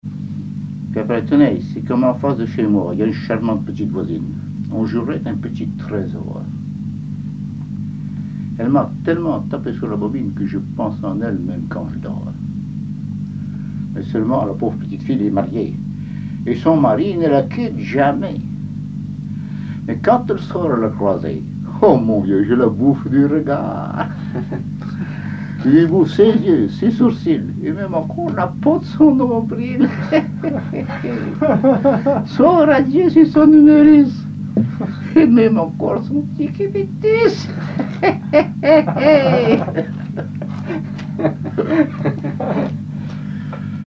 Localisation Saint-Hilaire-de-Riez
Genre récit